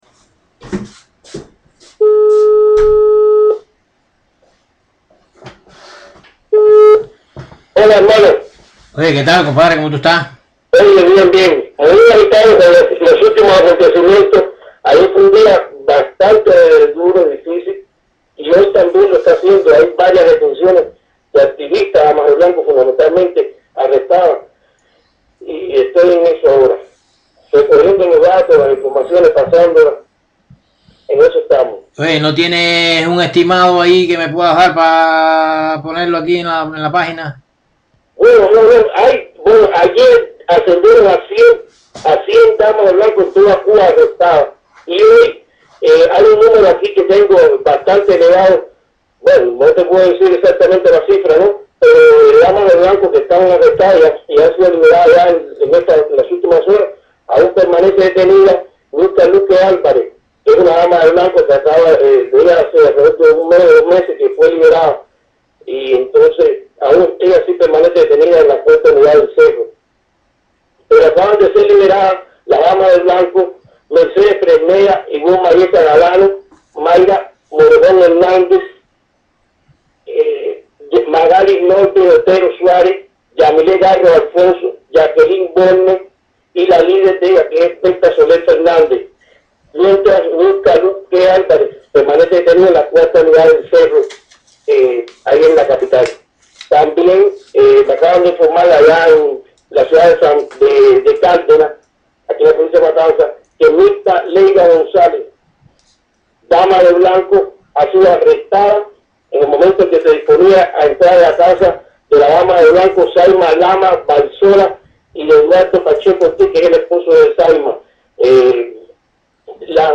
Este es un reporte